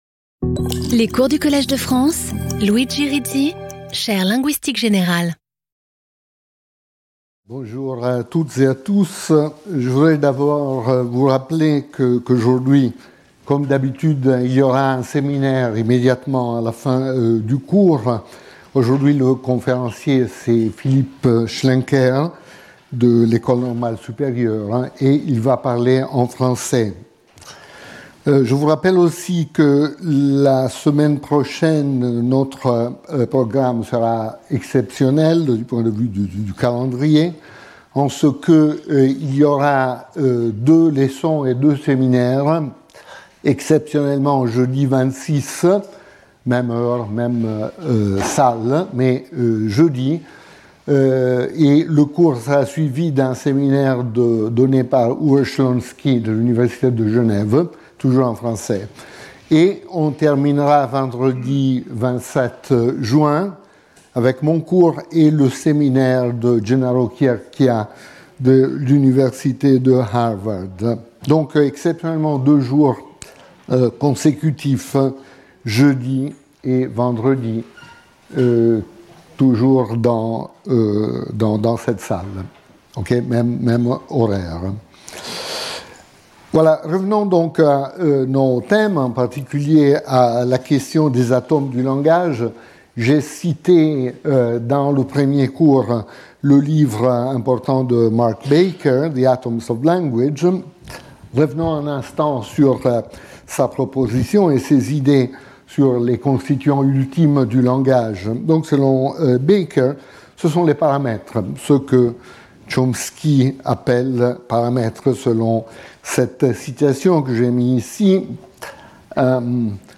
Luigi Rizzi Professeur du Collège de France
Cours